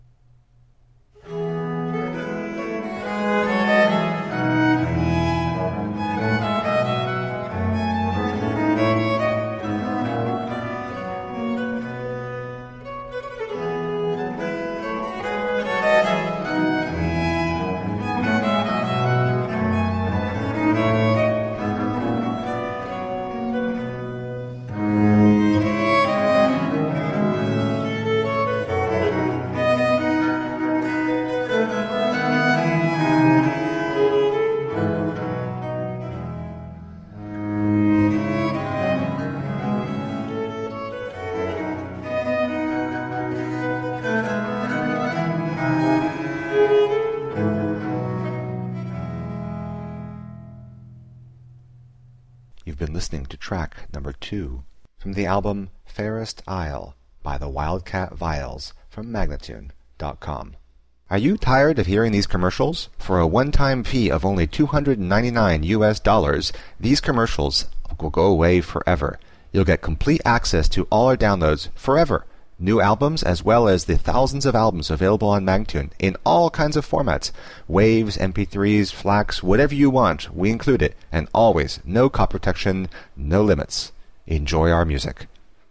Viola da gamba girls gone wild!!.